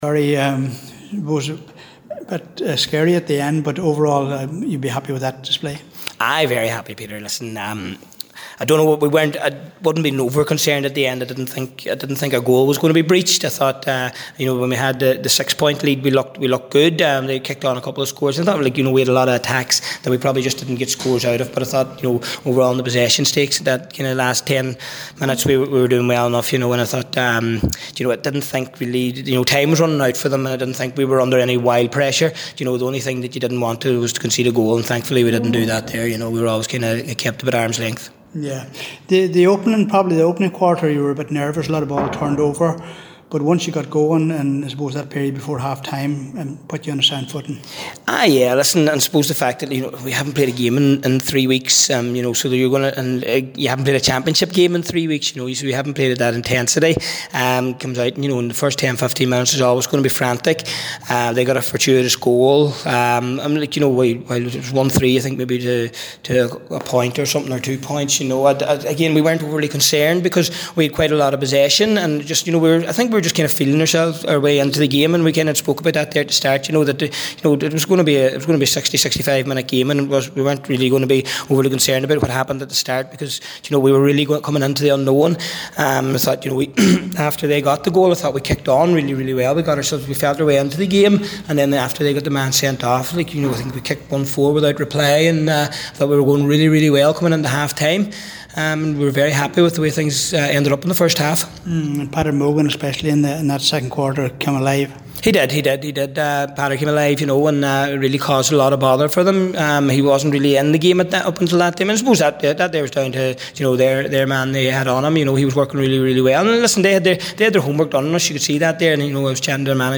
After the game